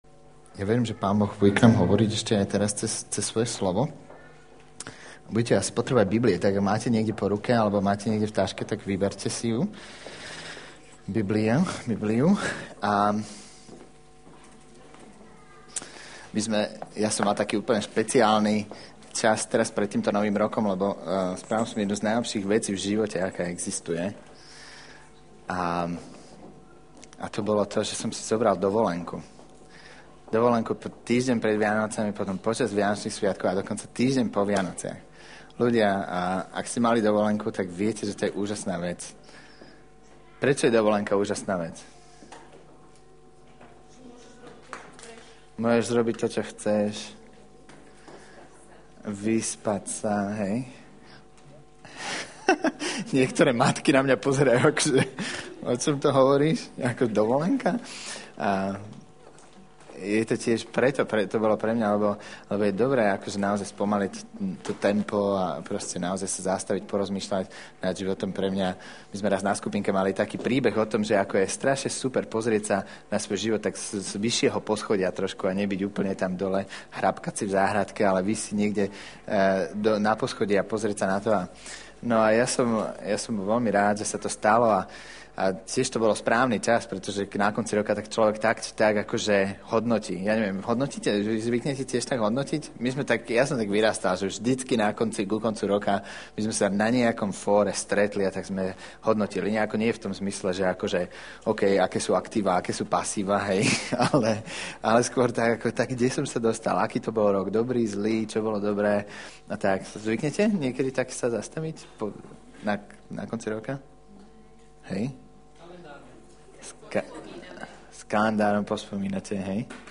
Nahrávka kázne Kresťanského centra Nový začiatok z 7. januára 2007